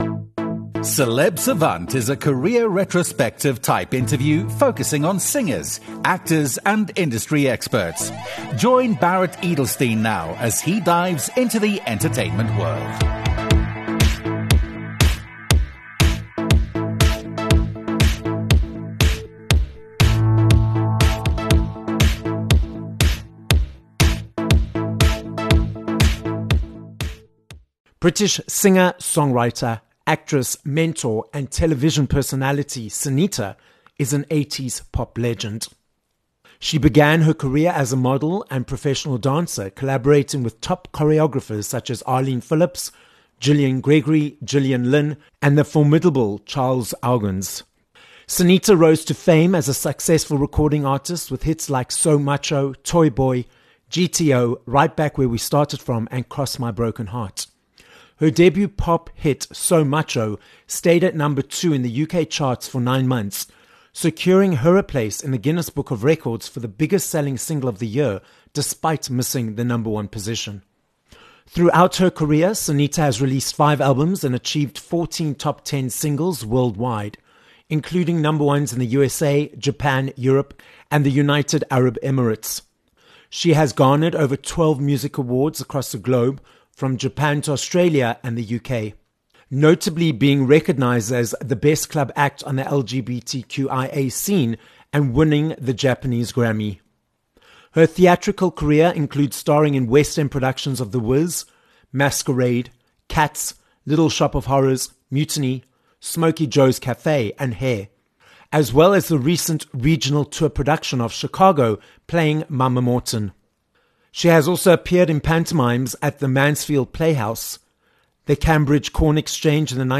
6 Aug Interview with Sinitta